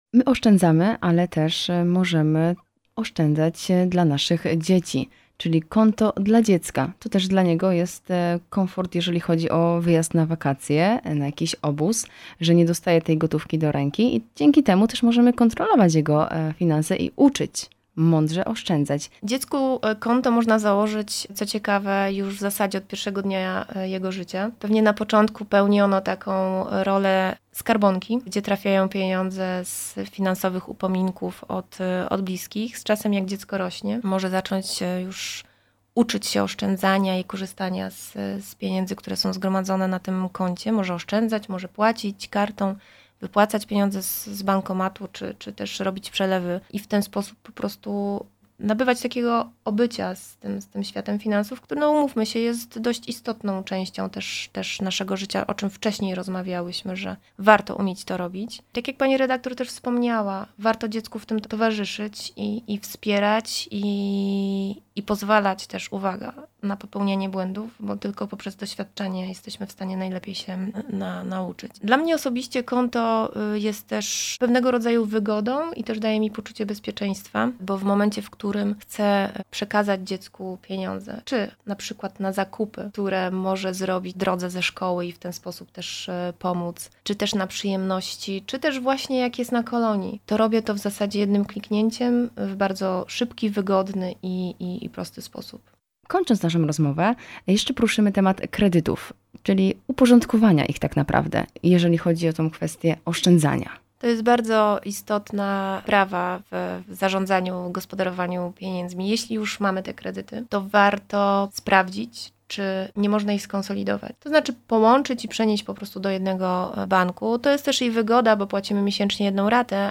Jak mądrze oszczędzać? Rozmowa